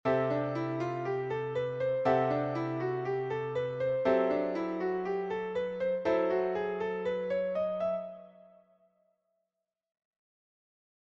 Improvisation Piano Jazz
Accord #11